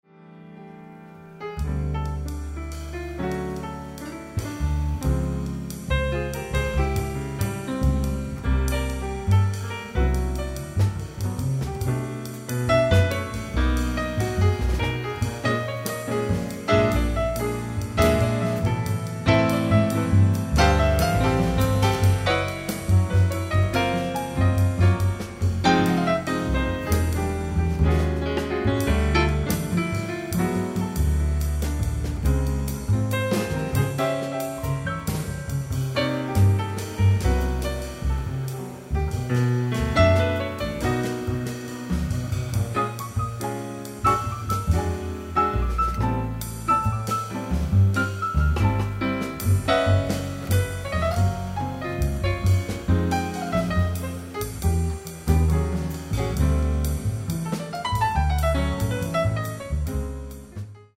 piano
acoustic bass
drums